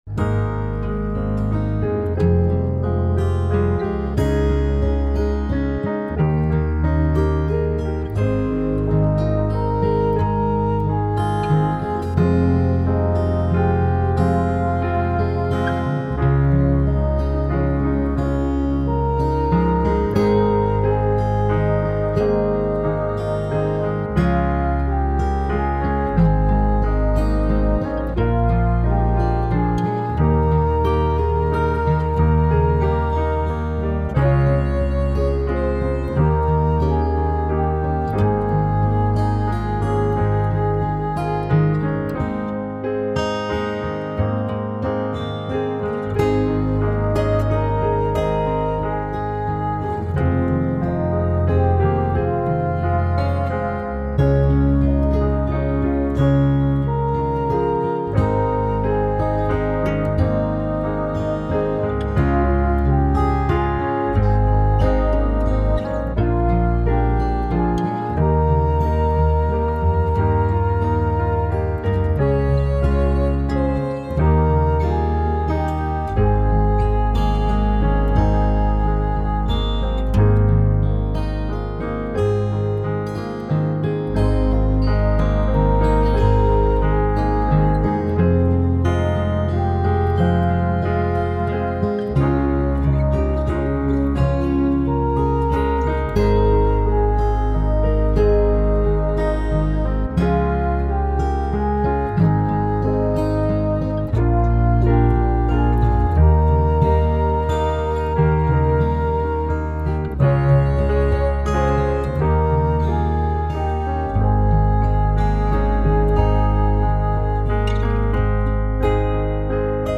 very gentle and soothing